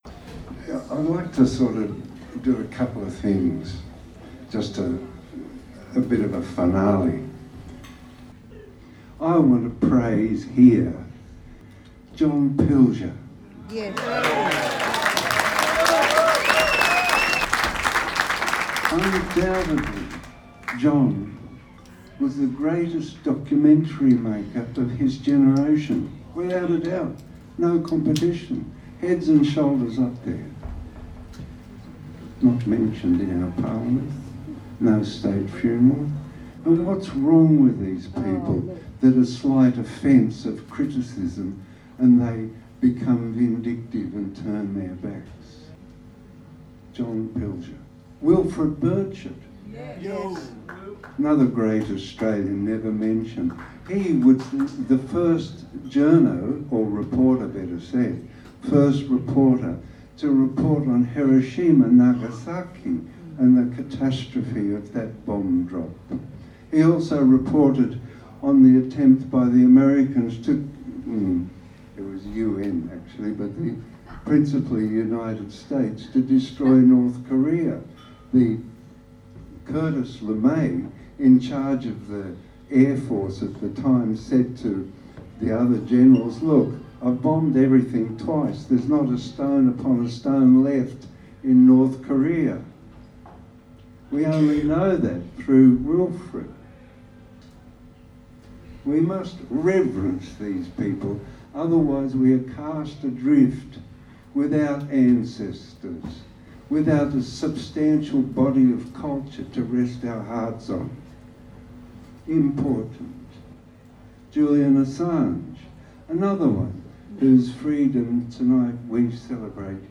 A week after Julian Assange’s release from Belmarsh prison, a boisterous gathering of 200 very happy Assange supporters packed the St Kilda Bowls Club in Melbourne to celebrate Julian Assange’s 53 birthday on July 3.